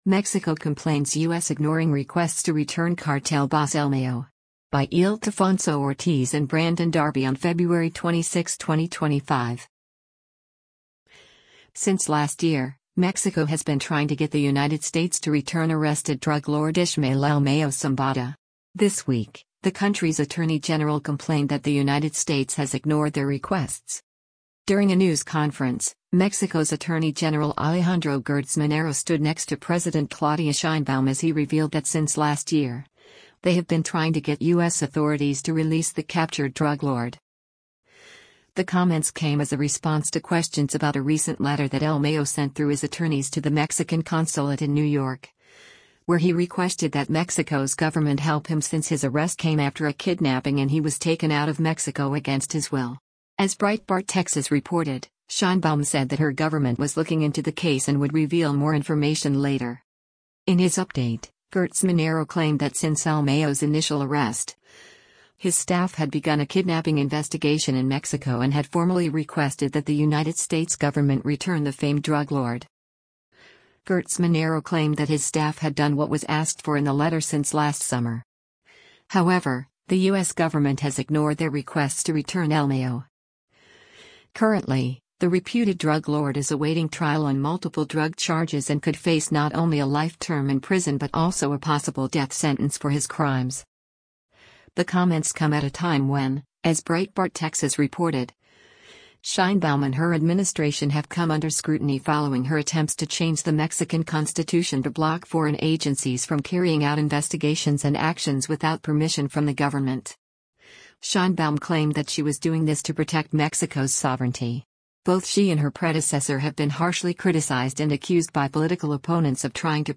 Mexican Attorney General Alejandro Gertz Manero talks about his efforts to have drug lord
During a news conference, Mexico’s Attorney General Alejandro Gertz Manero stood next to President Claudia Sheinbaum as he revealed that since last year, they have been trying to get U.S. authorities to release the captured drug lord.